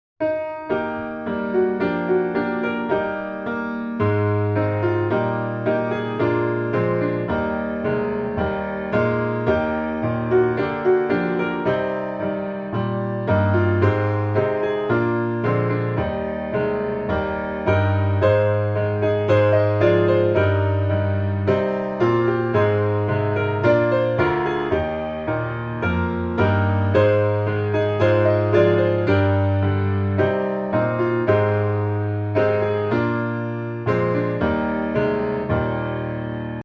Gospel
Eb Major